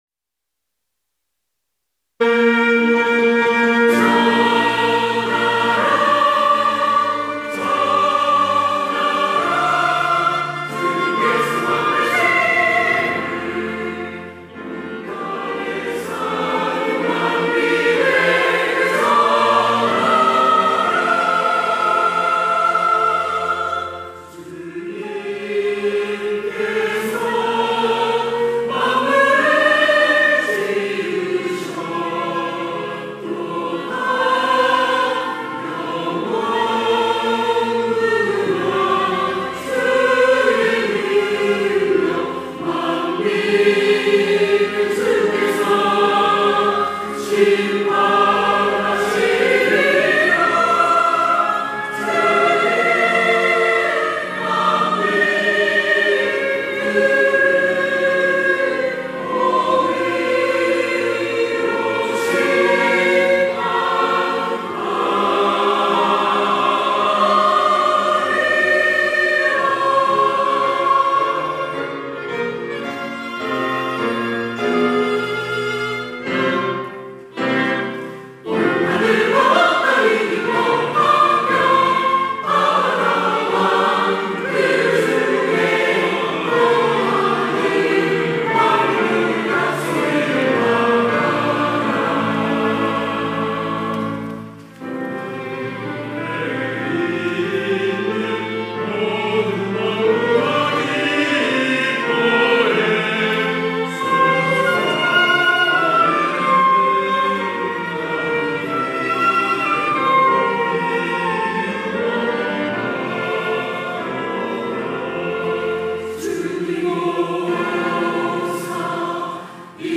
호산나(주일3부) - 주는 왕이시니
찬양대